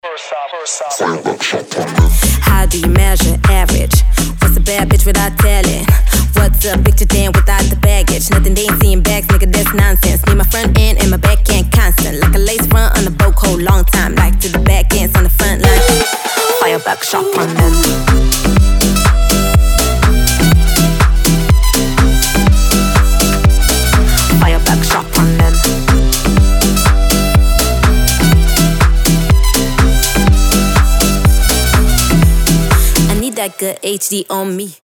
• Качество: 320, Stereo
поп
позитивные
dance
Саксофон
Позитивный ритмичный рингтон на звонок вашего телефона.